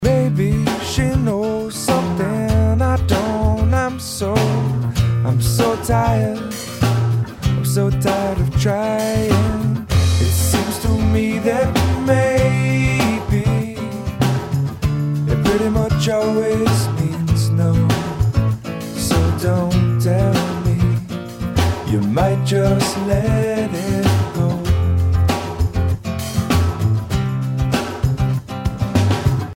The Verse of the song has a guitar that appears to be almost completely right panned with only a bit of signal coming through on the left channel. However in the chorus the guitar on the left comes in and the guitar can be heard both on left and right channels.